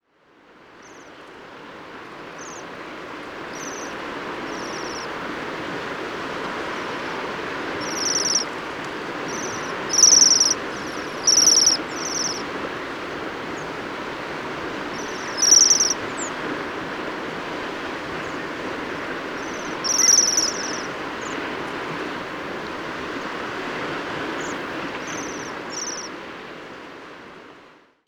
081219, Bohemian Waxwing Bombycilla garrulus, calls in flight